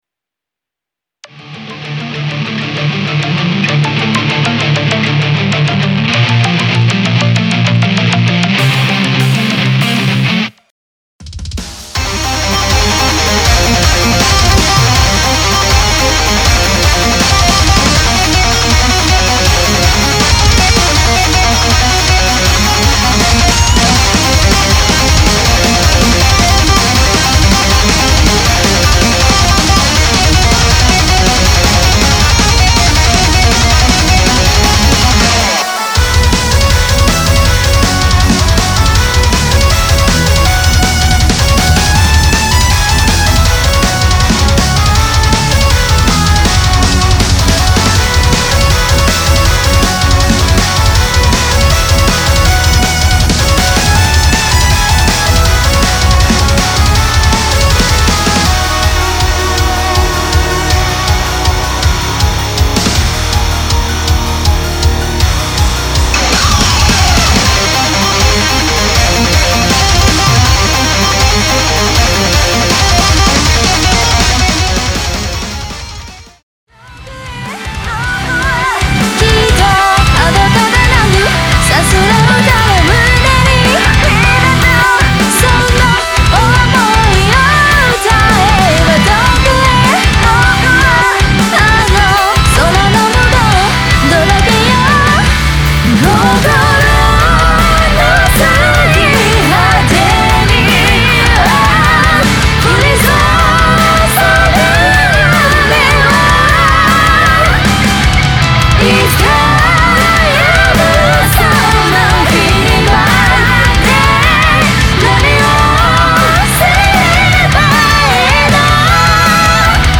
风格类型Rock，其他电子，Jpop，其他
Vocal